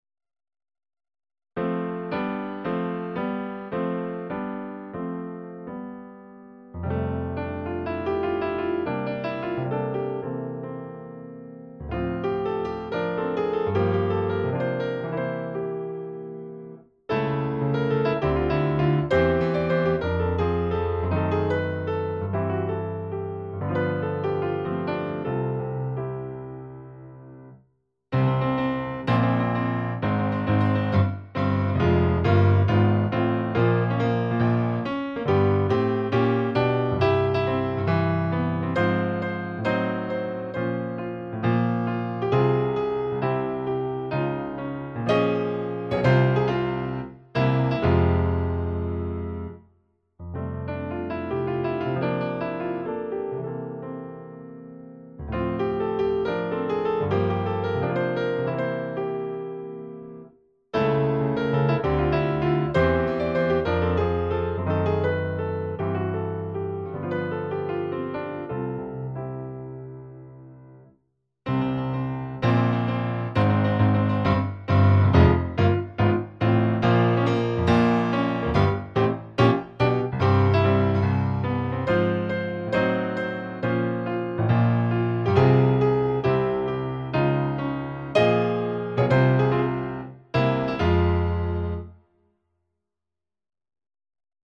akompaniament